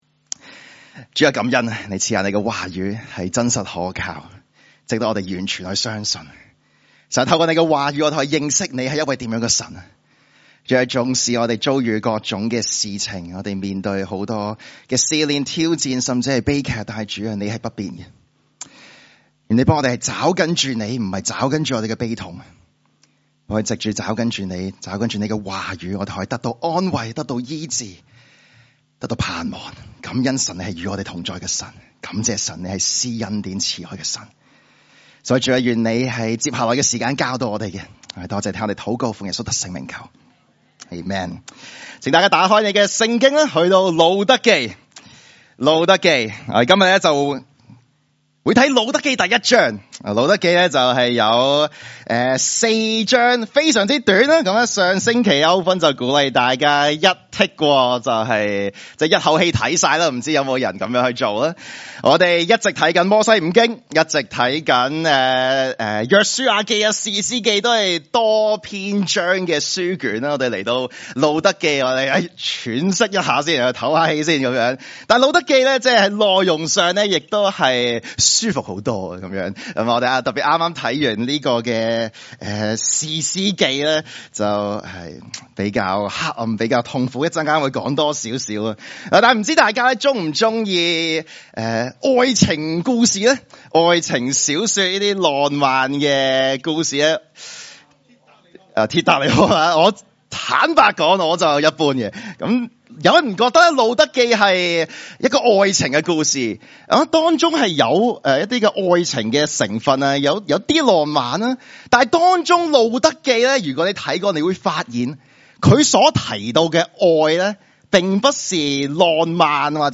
來自講道系列 "解經式講道"